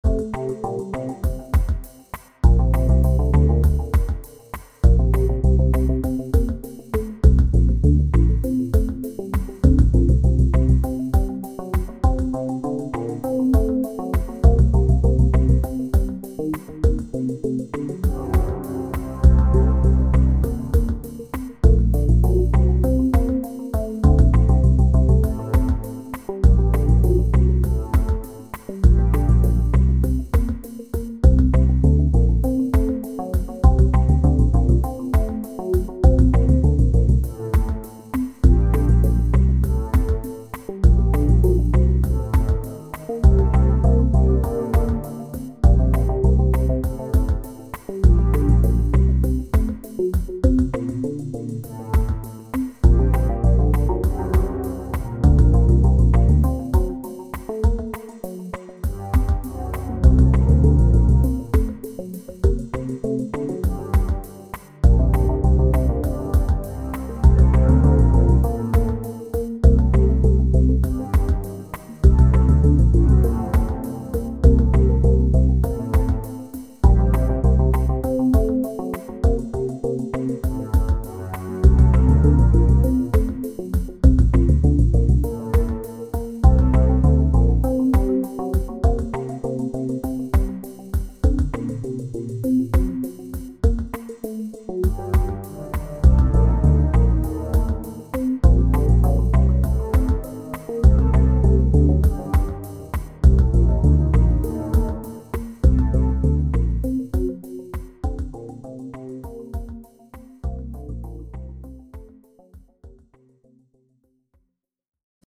Voici 3 ambiances musicales pour illustrer les capacités de la Société Henon.